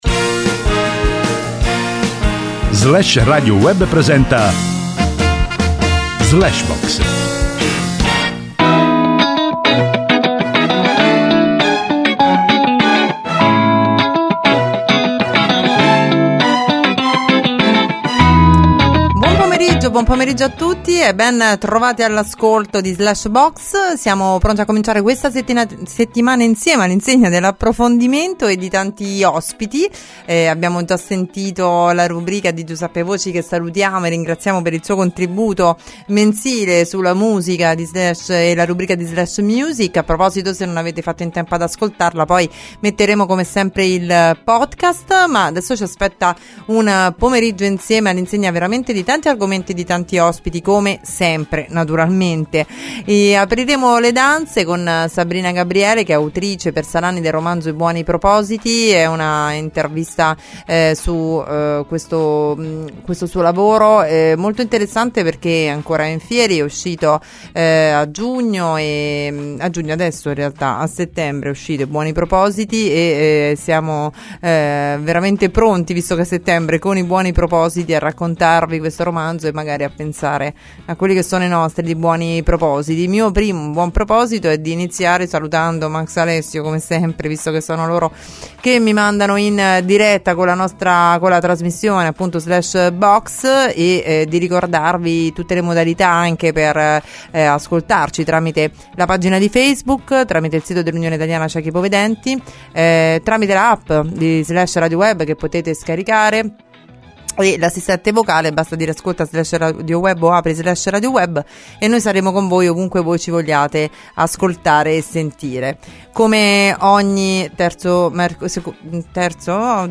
Ascolta l’intervista sul sito di Flash Radio Web >>>